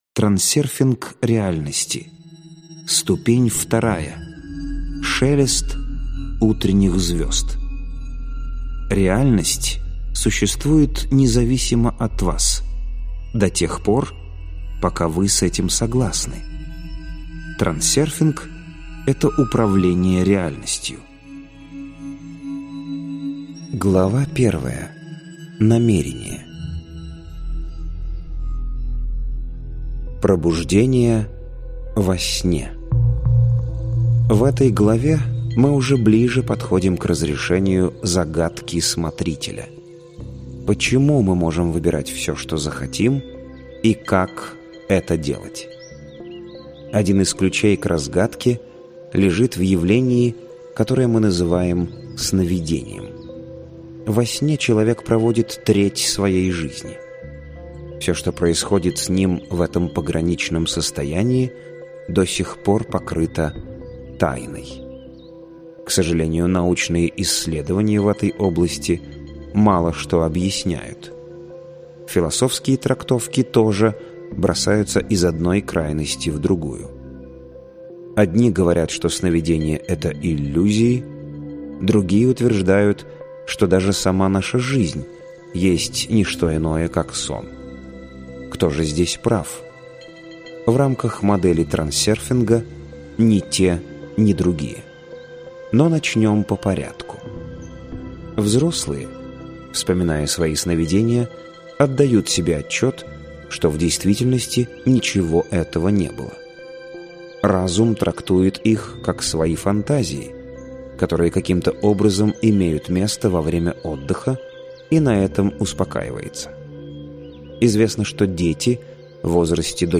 Аудиокнига Трансерфинг реальности. Ступень II: Шелест утренних звезд - купить, скачать и слушать онлайн | КнигоПоиск